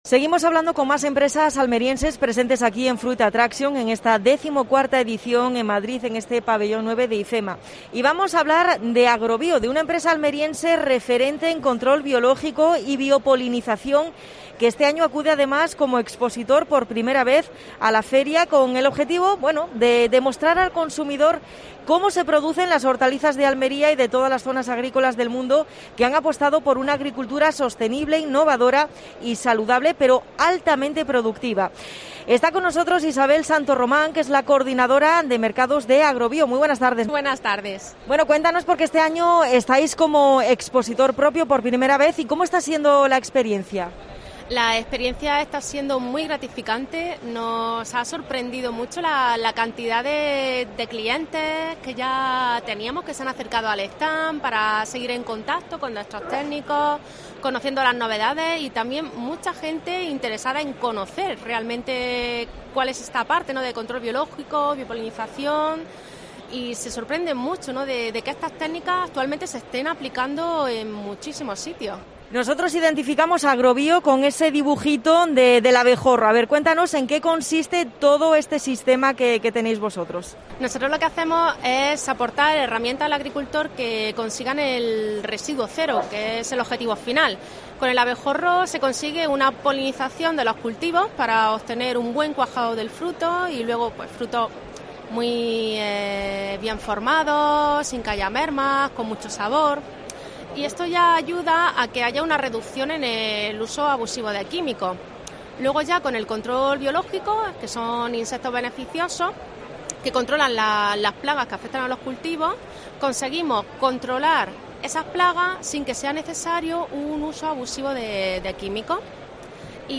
AUDIO: Entrevista a Agrobío.